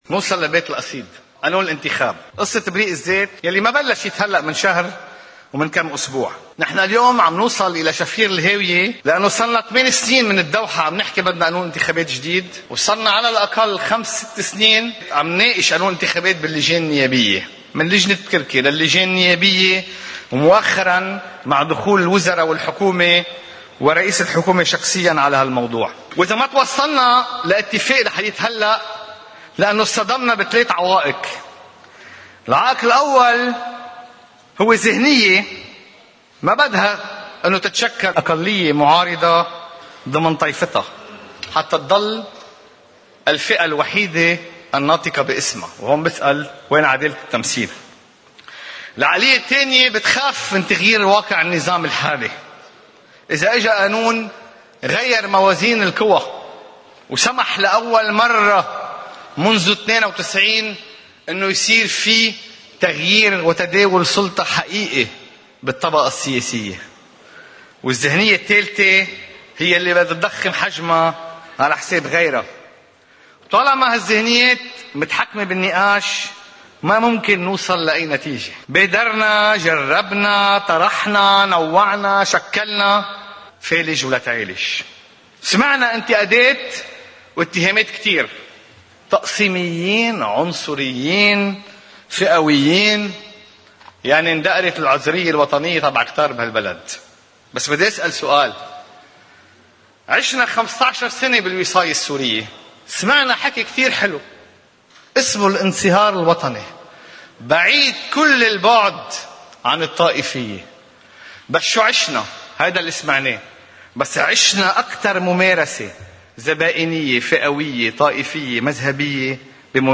مقتطف من حديث النائب آلان عون فيما يتعلّق قانون الإنتخاب، في جلسة مساءلة الحكومة: